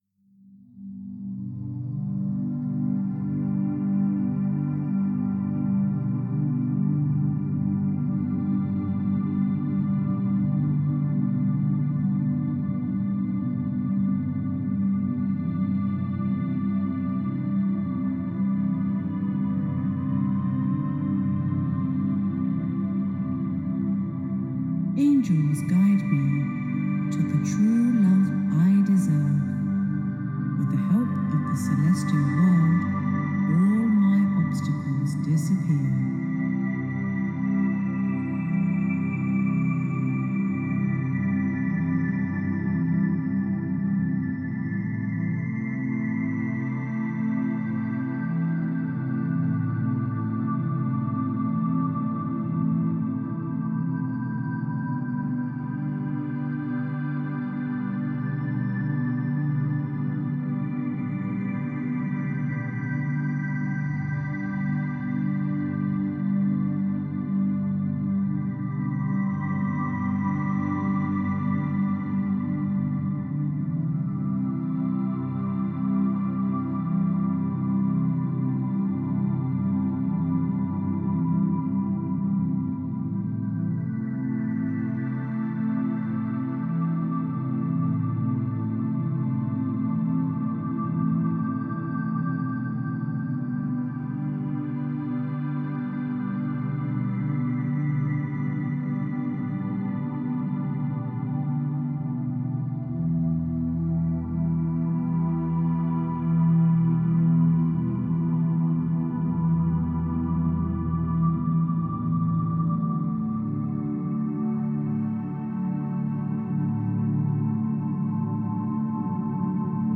celestial music